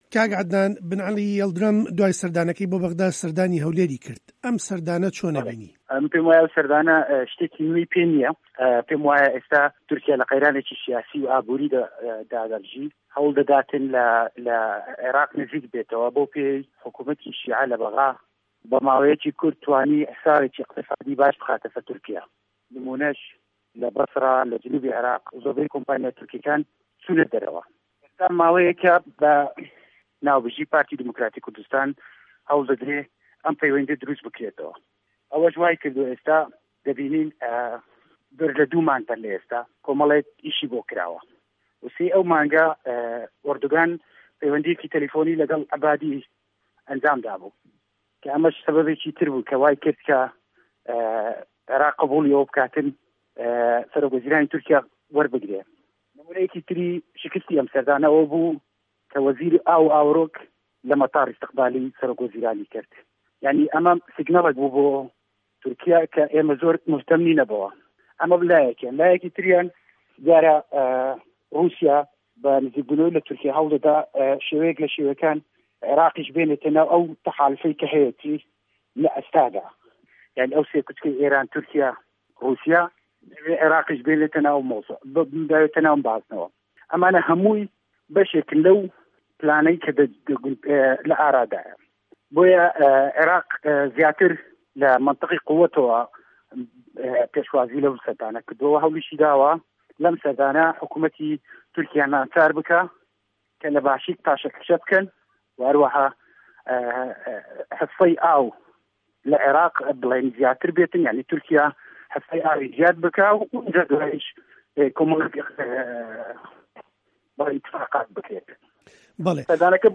زانیاری زیاتر له‌ده‌قی وتووێژه‌که‌دایه‌.